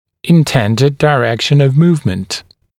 [ɪn’tendɪd dɪ’rekʃn əv ‘muːvmənt] [daɪ-][ин’тэндид ди’рэкшн ов ‘му:вмэнт] [дай-]задуманное направление движение, намеченное направление движения